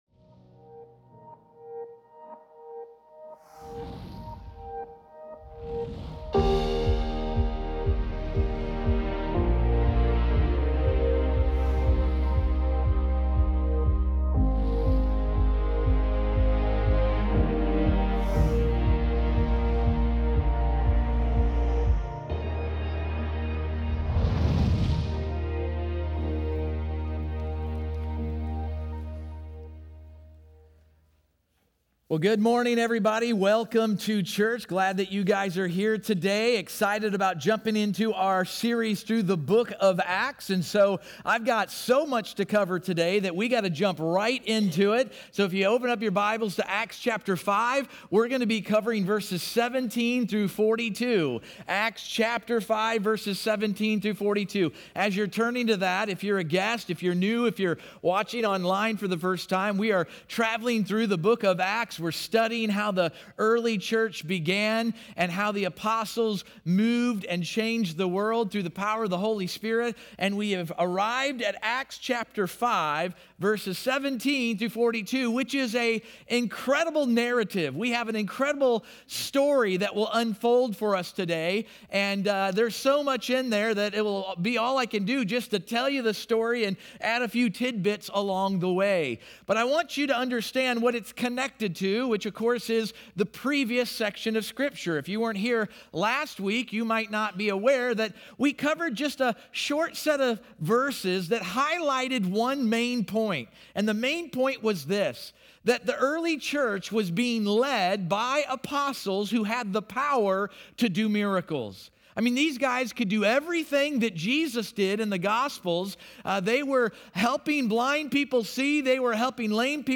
acts-5-17-sermon-audio.mp3